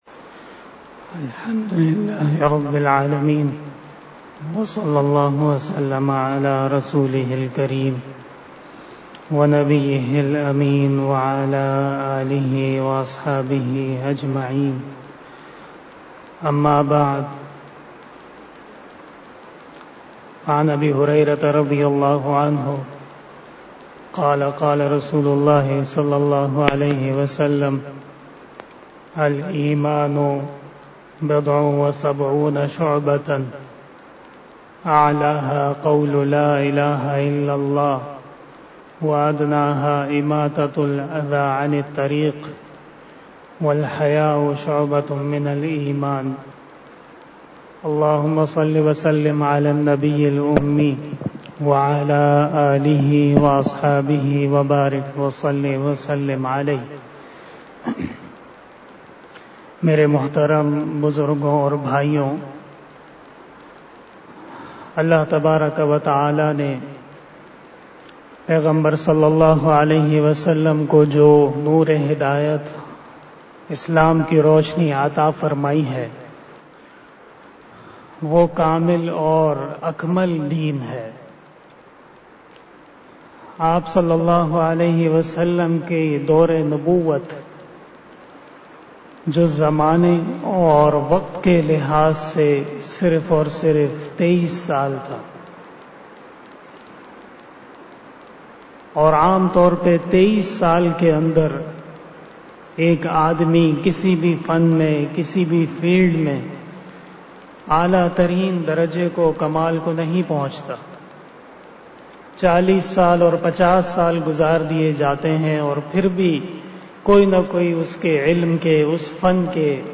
11:00 PM 472 Shab-e-Jummah 2020 بیان شب جمعہ 30 شعبان المعظم 1441 ھجری 23 اپریل 2020 ء ۔